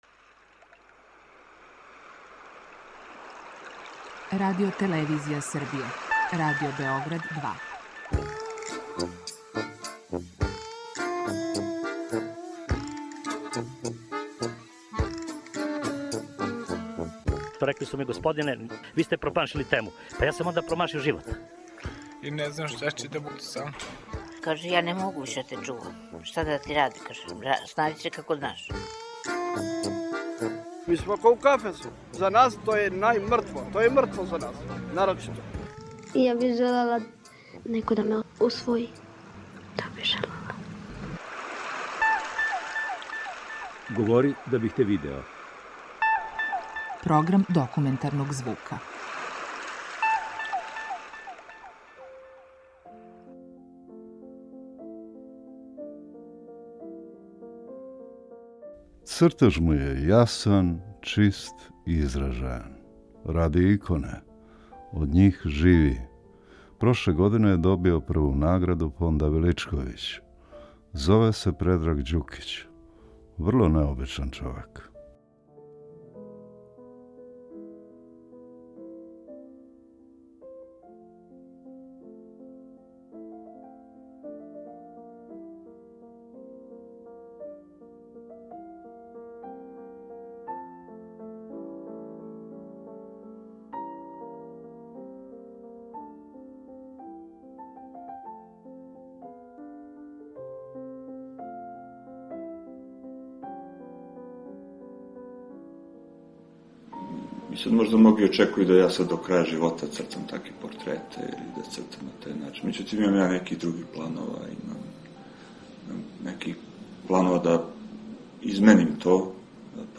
Dokumentarni program